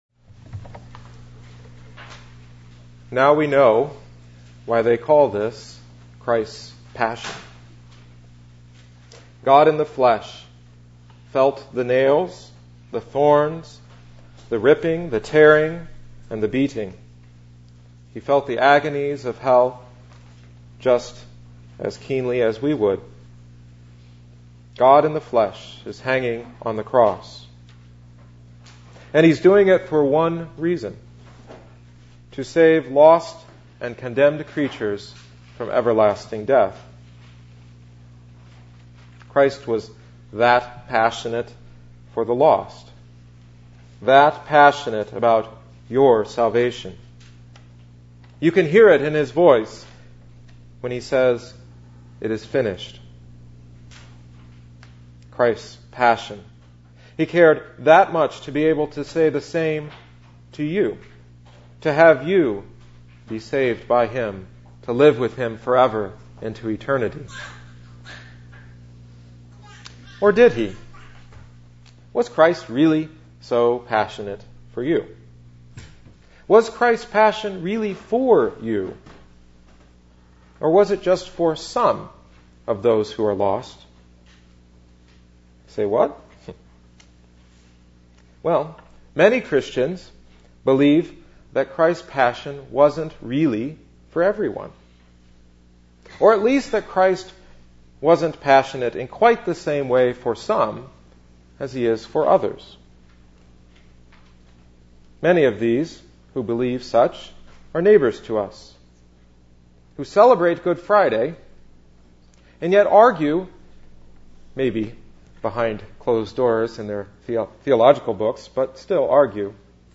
Good Friday Chief Service 2011